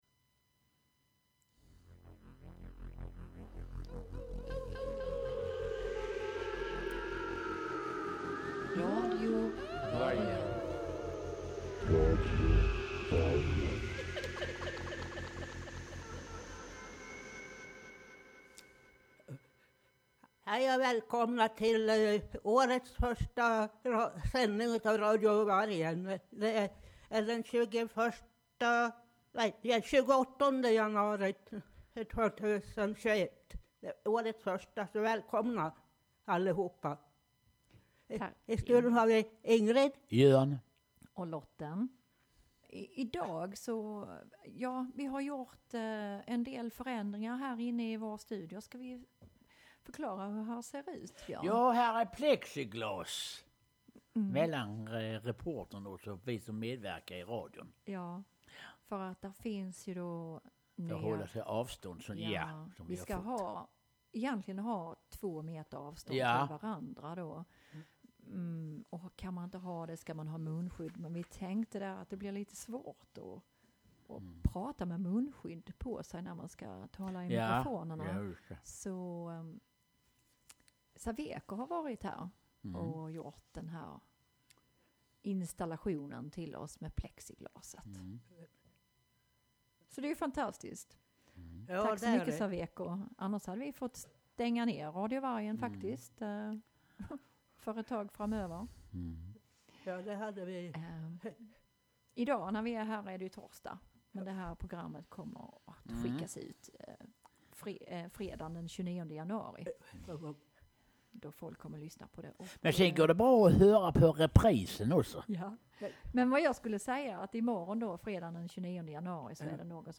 Årets första sändning av Radio Vargen spelas in med ett plexiglas mellan deltagarna, som en säkerhetsåtgärd för att minska eventuell smittospridning av Covid 19.